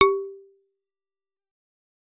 Xyl_GSharp2.L.wav